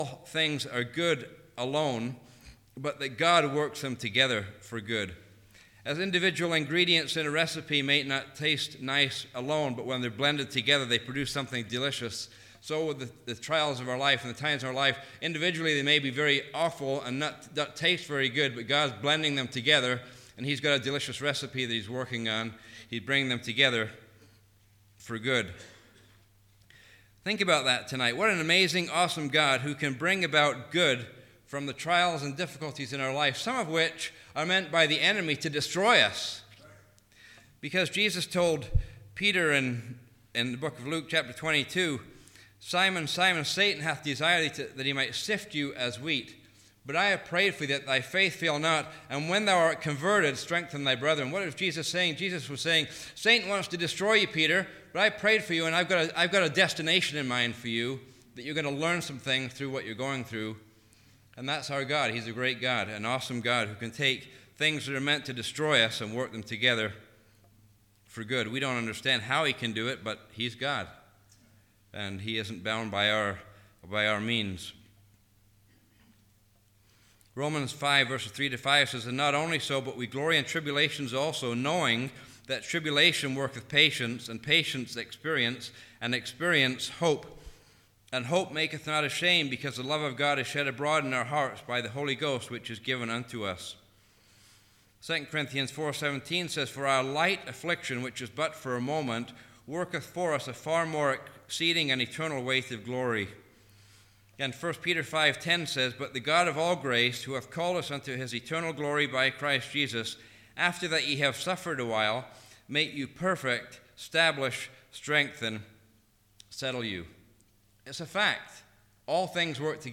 The Christian’s Consolation | Sermons